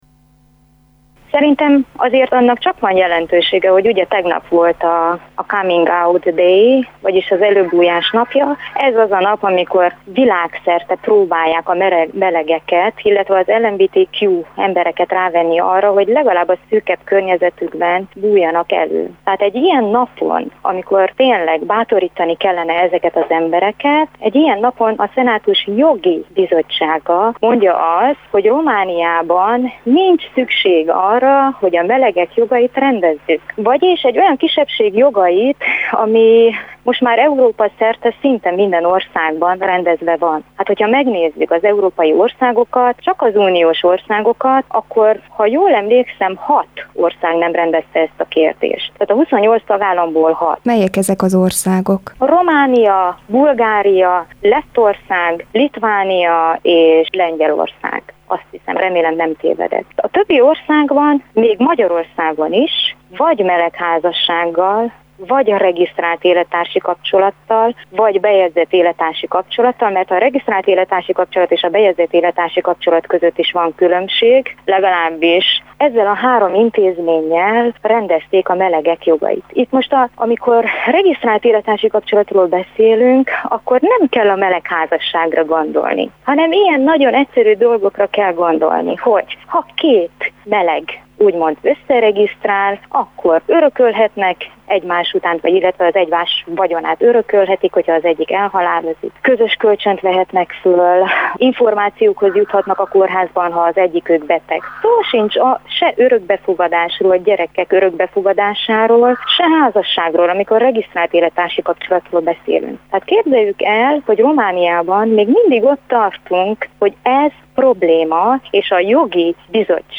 újságírót, aktivistát